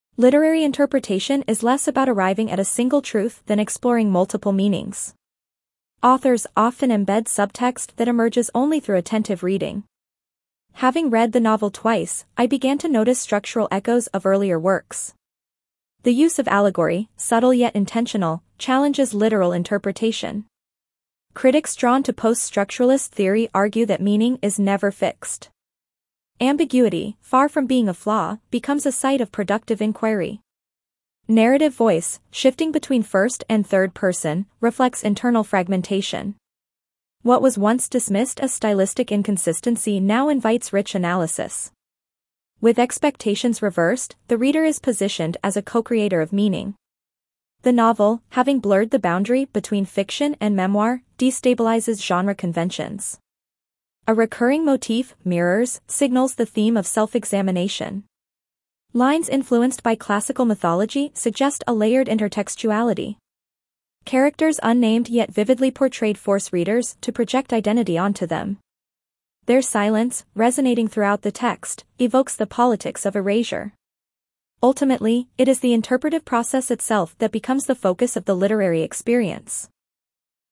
C2 Dictation - Literary Interpretation